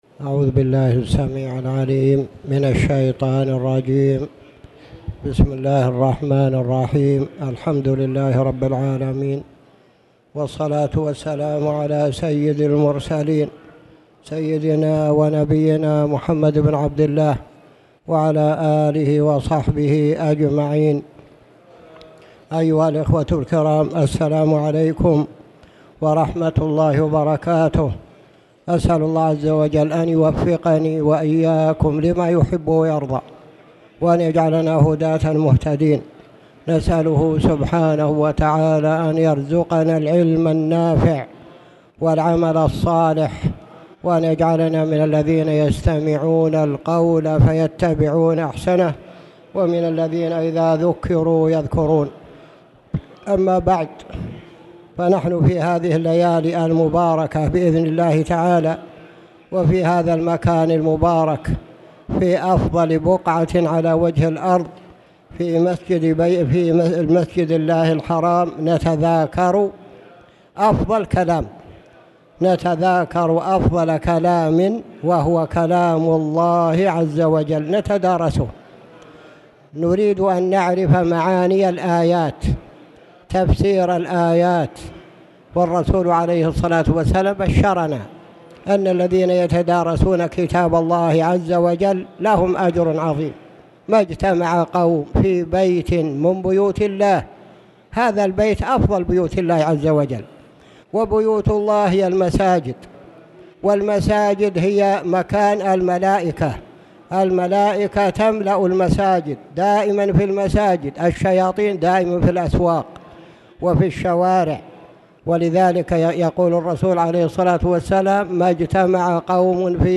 تاريخ النشر ٢٦ ربيع الثاني ١٤٣٨ هـ المكان: المسجد الحرام الشيخ